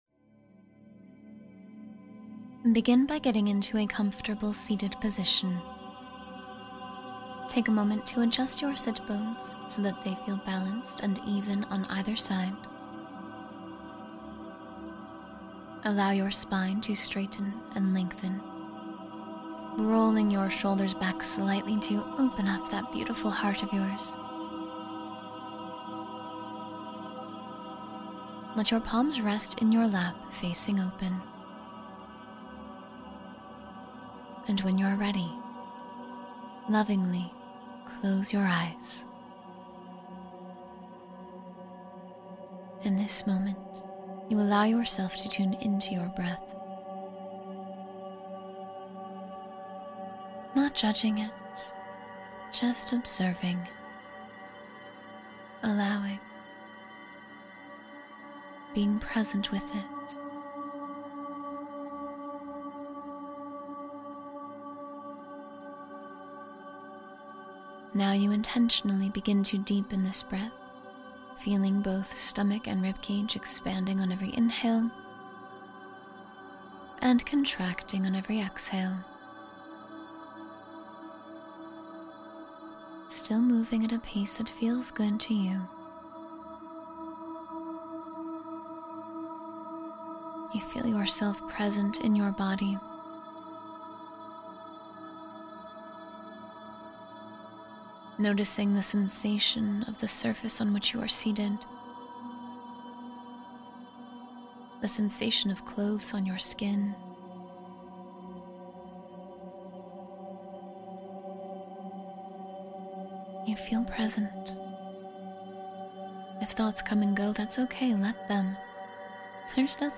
Positive Energy Activation — 10-Minute Meditation for Uplift and Clarity